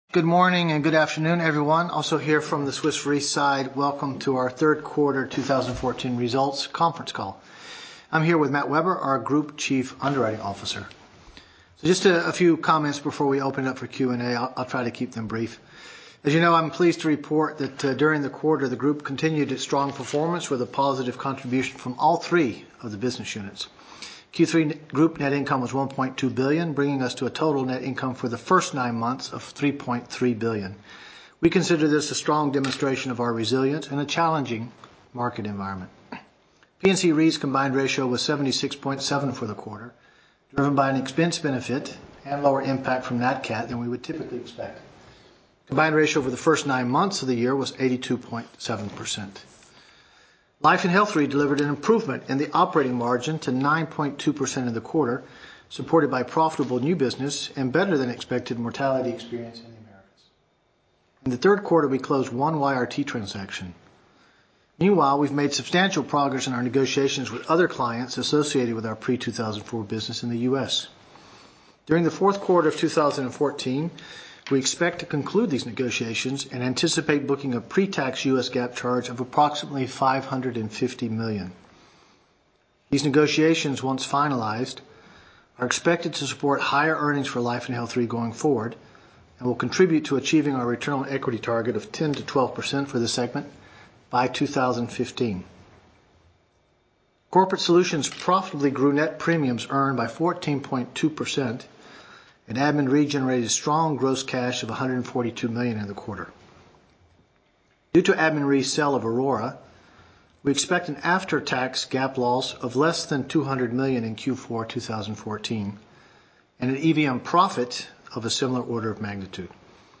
Analysts Conference call recording
2014_q3_qa_audio.mp3